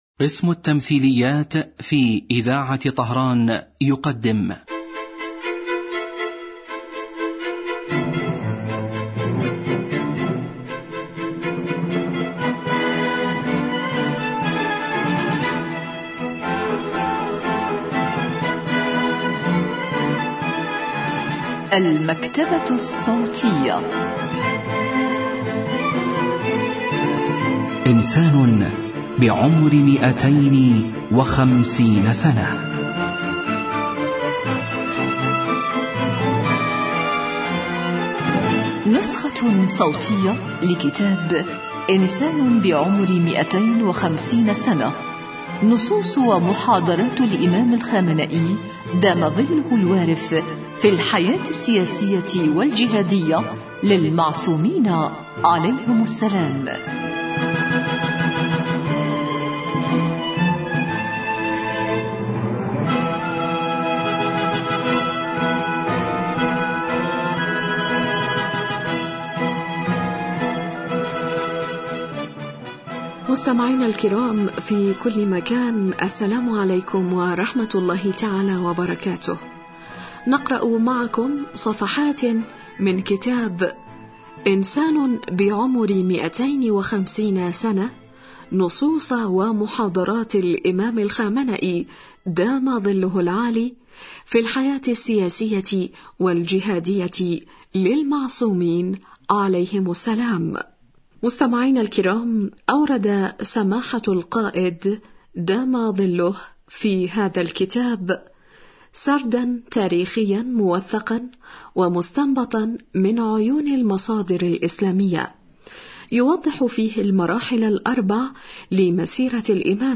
إذاعة طهران- إنسان بعمر 250 سنة: نسخة صوتية لكتاب إنسان بعمر 250 سنة للسيد علي الخامنئي في الحياة السياسية والجهادية للمعصومين عليهم السلام.